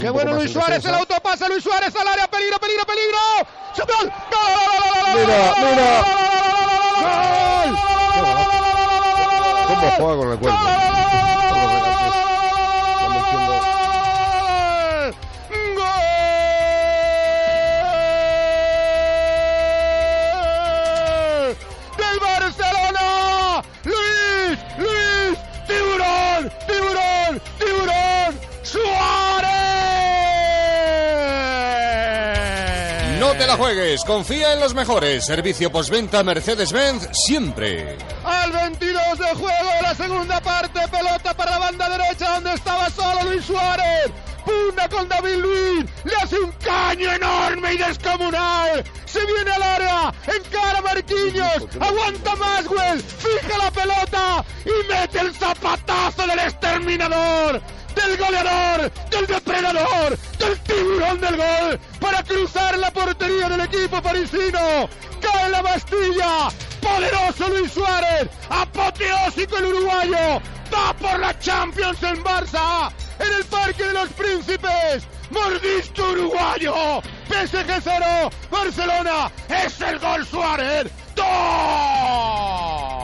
Transmissió del partit Paris Saint Germanin - Futbol Club Barcelona dels quarts de final de la Copa d'Europa de futbol masculí.
Esportiu